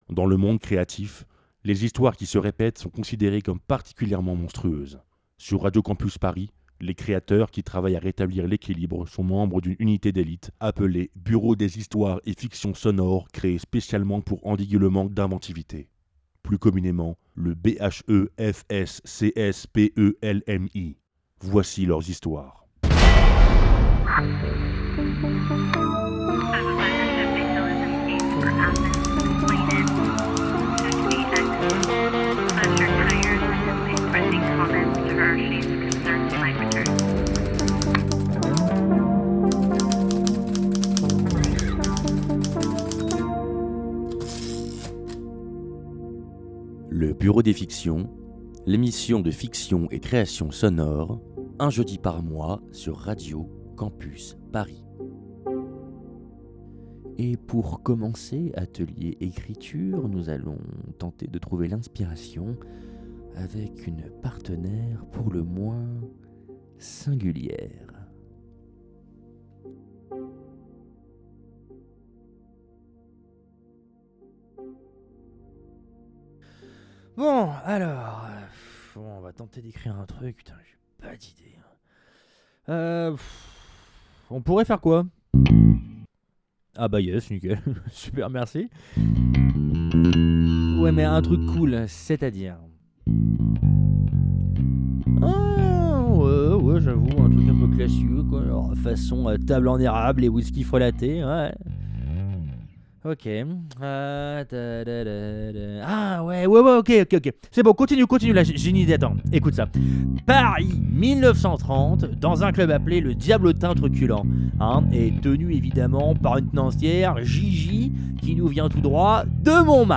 Partager Type Création sonore Autre jeudi 24 octobre 2024 Lire Pause Télécharger Au programme de ce nouvel épisode, nous allons partir en quête d'inspiration avec une amie, pas comme les autres.